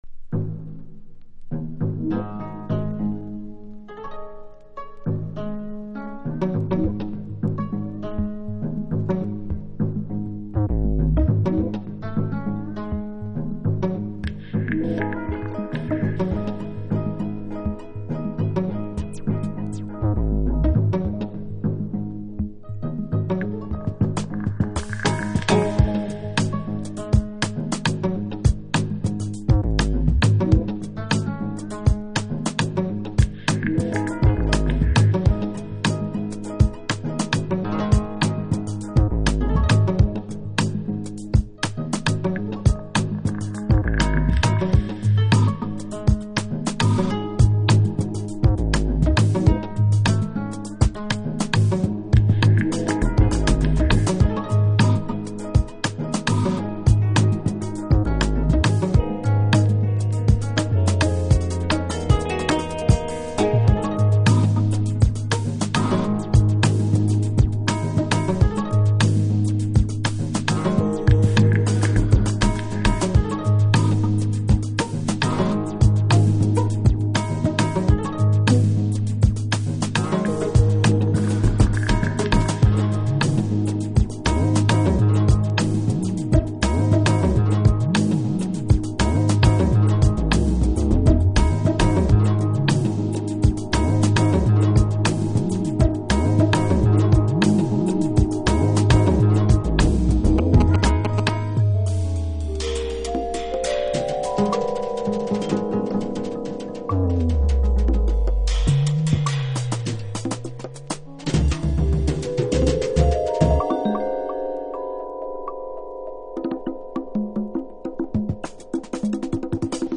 Future Jazz / Broken beats
雅楽を意識した旋律も粋ですね。LTJ Bukem以降のジャズ的なるドラムンベースも収録。